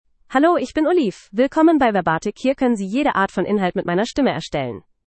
OliveFemale German AI voice
Olive is a female AI voice for German (Germany).
Voice sample
Listen to Olive's female German voice.
Female